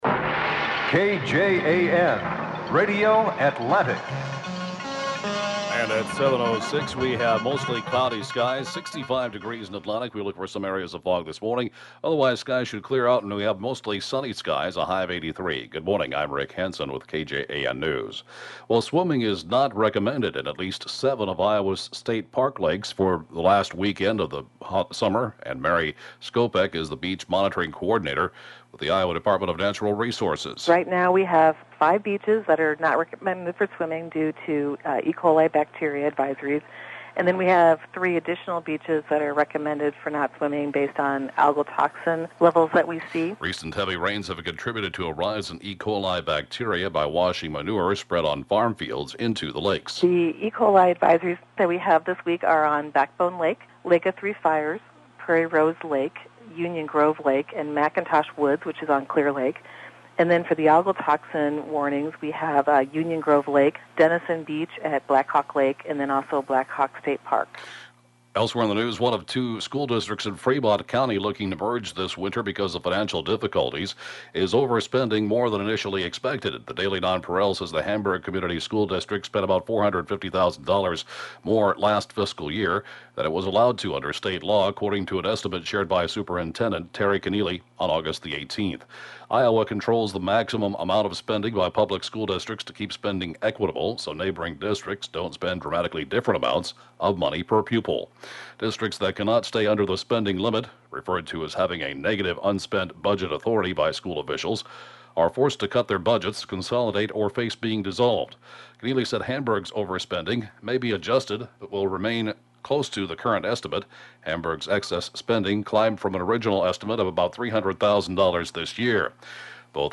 (Podcast) 7:06-a.m. News & funeral report, Sat. 8/30/2014